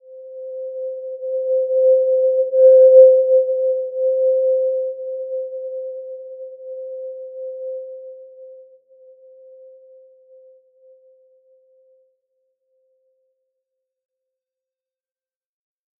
Simple-Glow-C5-mf.wav